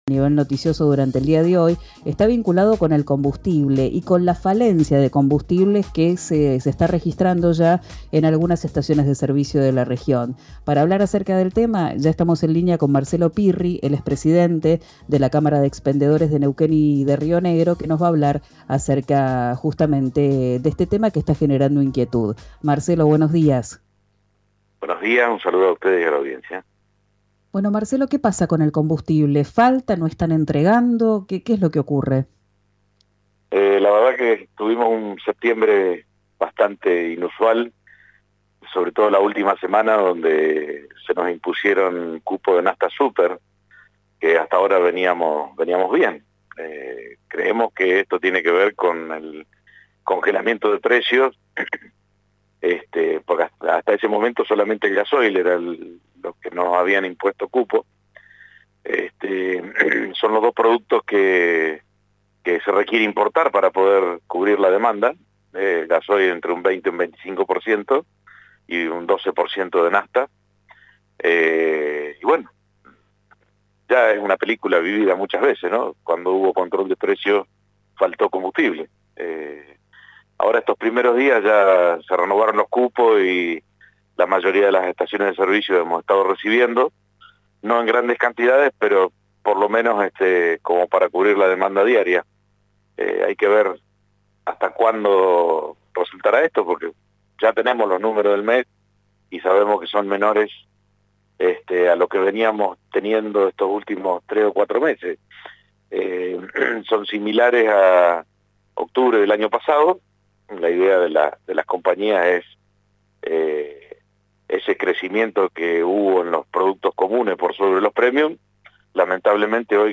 en diálogo con Arranquemos en RÍO NEGRO RADIO